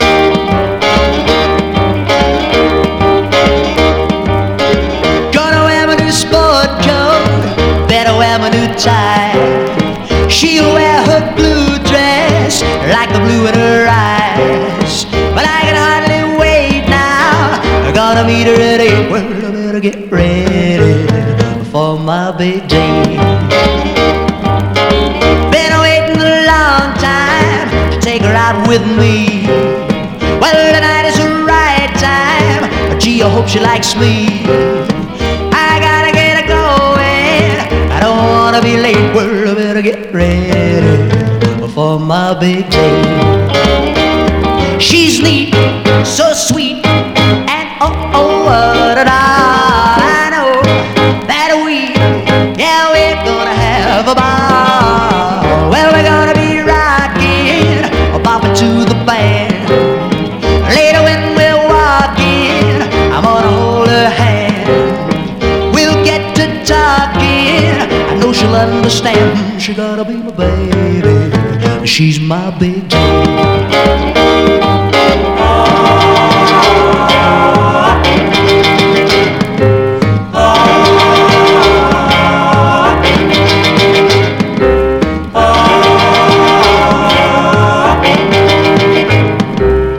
痛快ビートニク・コンピレーション！